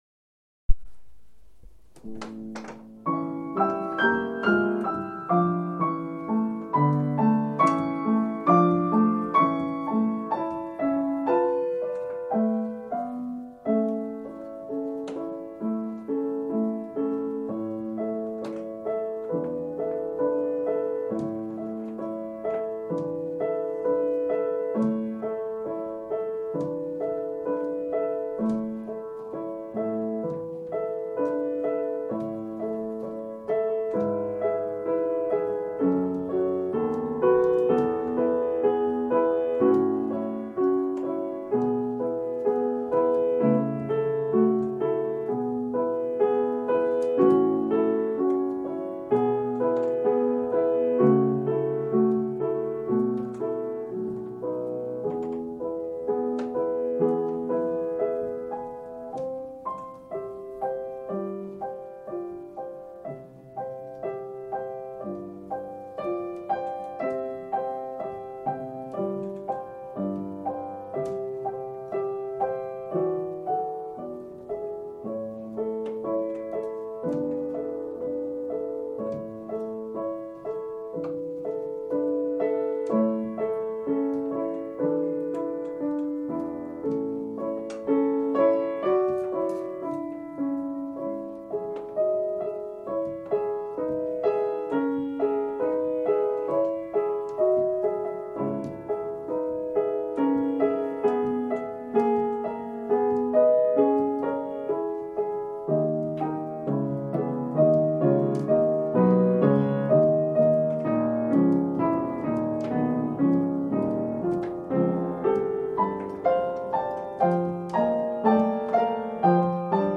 Dring 2nd Movement piano only